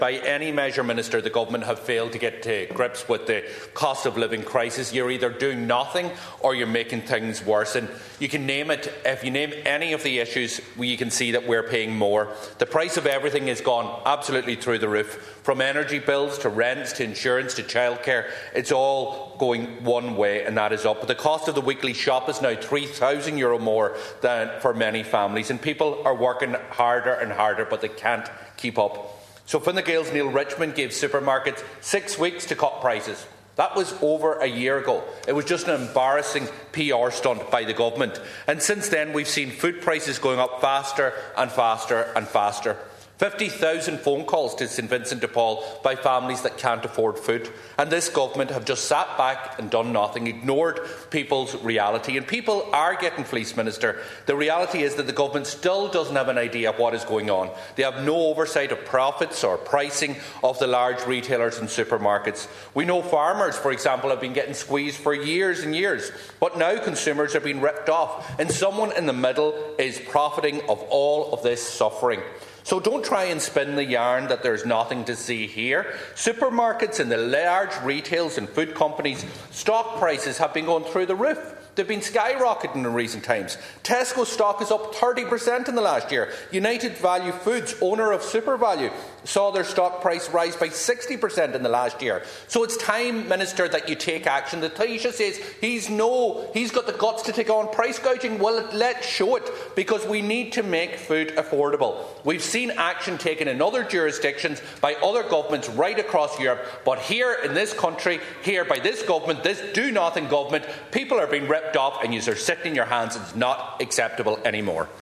Deputy Pearse Doherty says the Government has sat by and done nothing as people grapple with the cost of living: